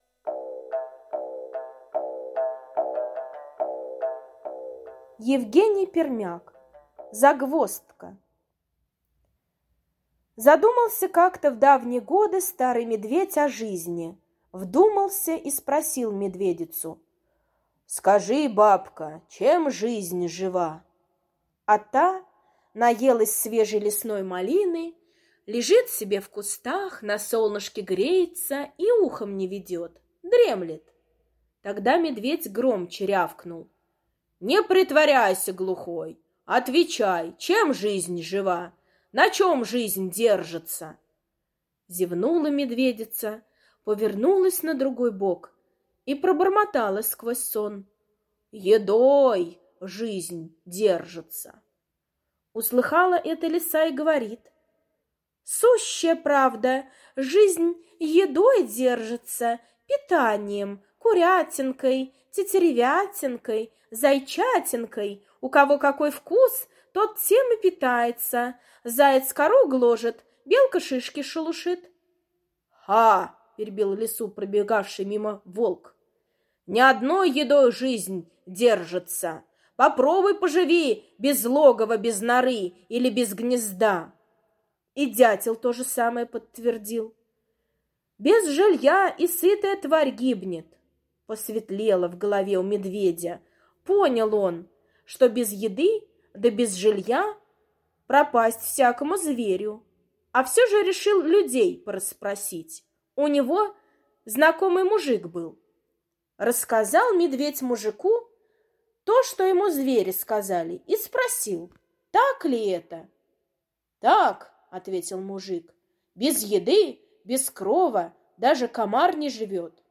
Загвоздка — аудиосказка Пермяка Е. Задумался однажды Медведь: что в жизни самое главное, без чего нельзя прожить.